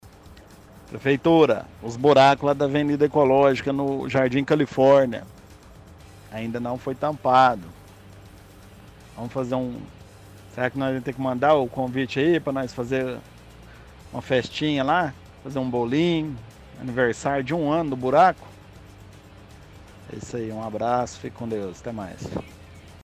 – Ouvinte reclama de buracos no Jardim Califórnia.